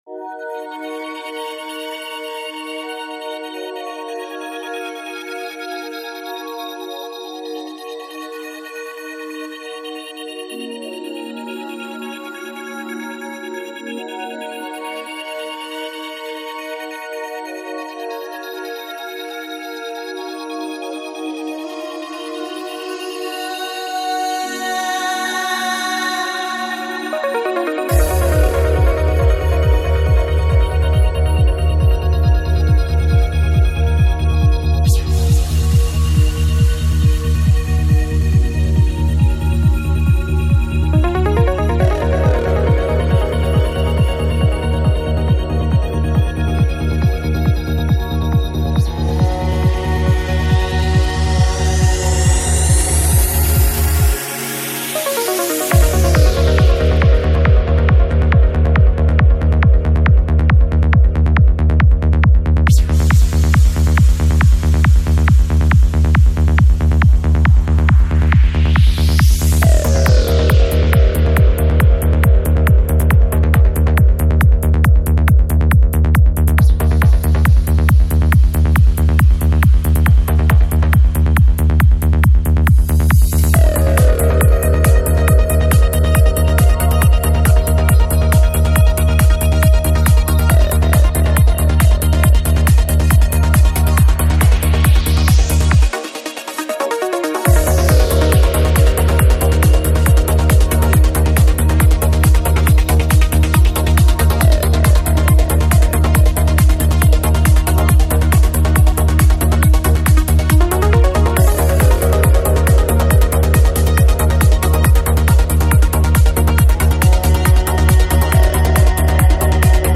Жанр: Psychedelic
Альбом: Psy-Trance